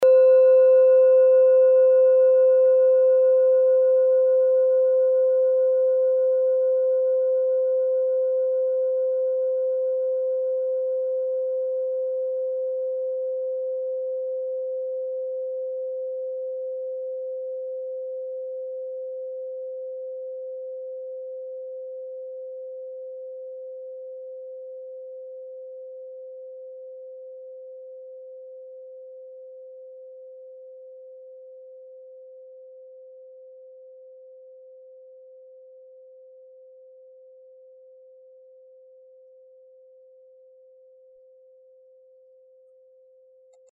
Kleine Klangschale Nr.1
Klangschale-Gewicht: 390g
Klangschale-Durchmesser: 11,9cm
Diese Klangschale ist eine Handarbeit aus Bengalen. Sie ist neu und ist gezielt nach altem 7-Metalle-Rezept in Handarbeit gezogen und gehämmert worden.
(Ermittelt mit dem Minifilzklöppel)
kleine-klangschale-1.mp3